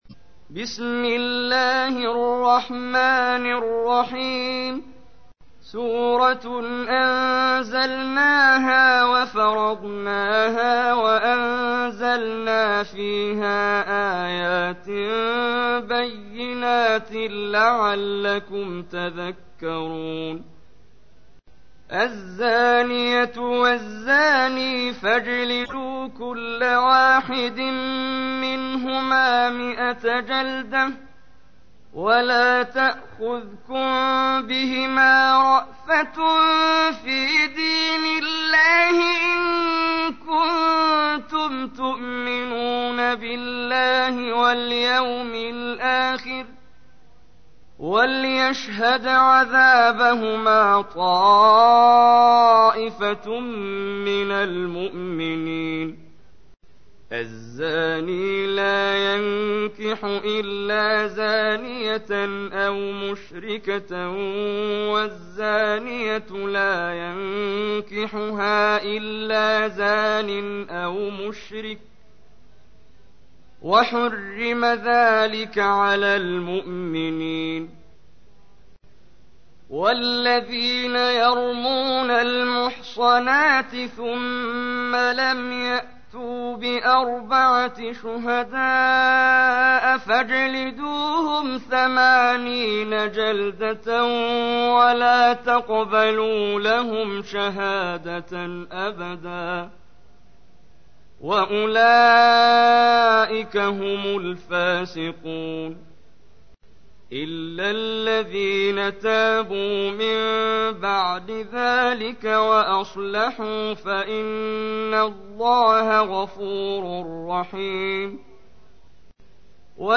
تحميل : 24. سورة النور / القارئ محمد جبريل / القرآن الكريم / موقع يا حسين